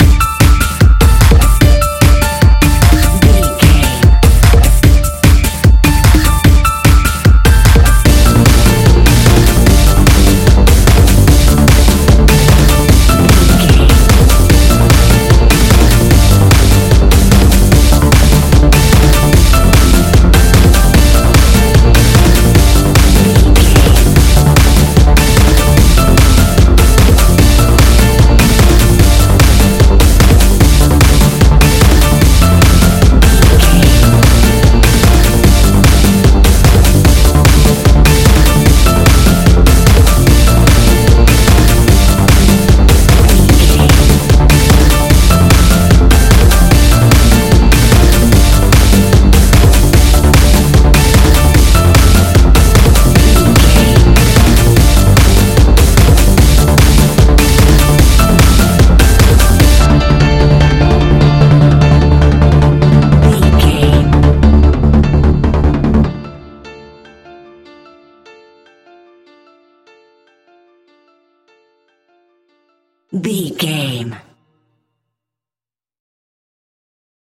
Aeolian/Minor
Fast
futuristic
energetic
uplifting
hypnotic
drum machine
piano
synthesiser
percussion
acid trance
uptempo
synth leads
synth bass